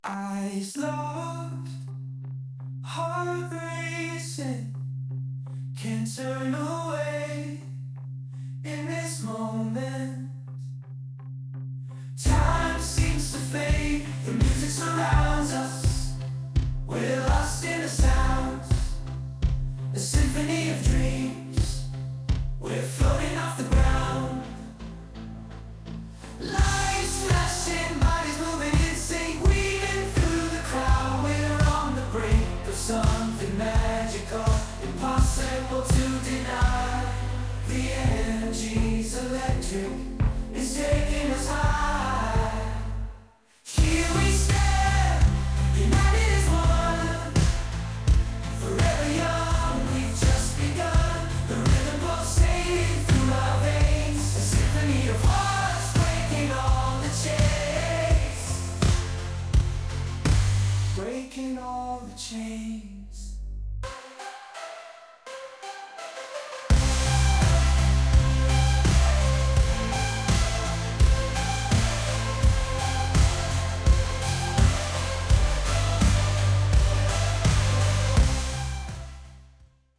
Electric Bass, Drums, Electric Guitars, Chorus
Genre: Blues and Rock